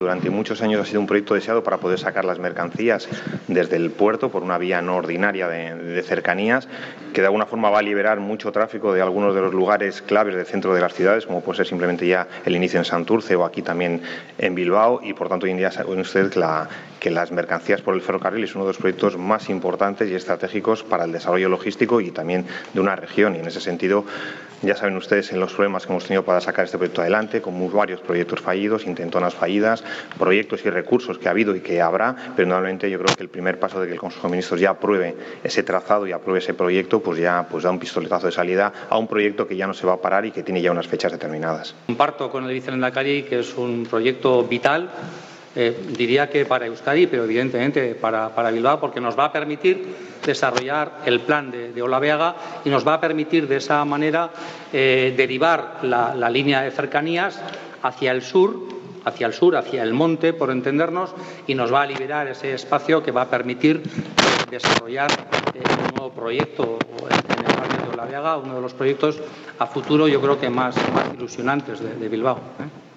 Declaraciones de Mikel Torres y Juan Mari Aburto sobre la Variante Sur Ferroviaria